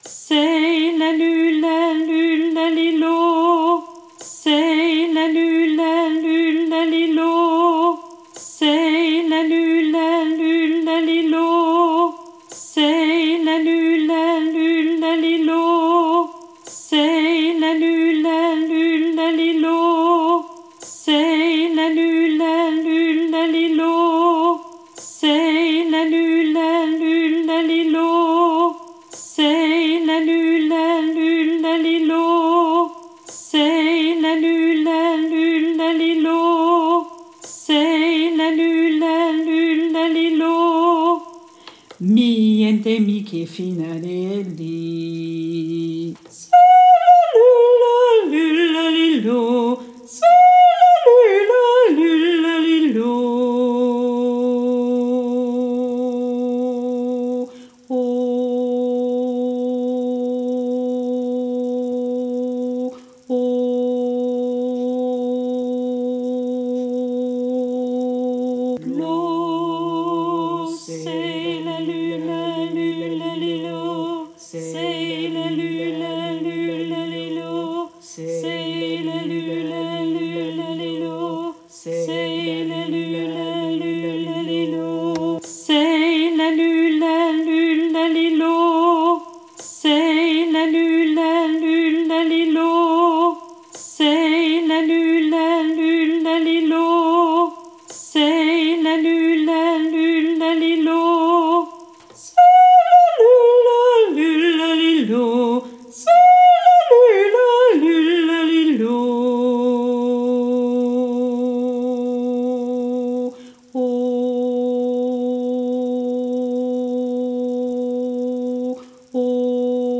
Baryton Basse